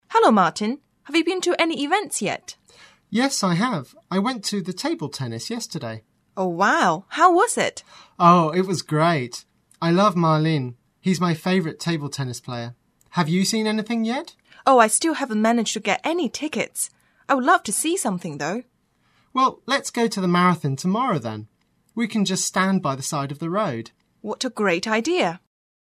英语初学者口语对话第12集：你去看了什么奥运赛事了吗？
english_47_dialogue_1.mp3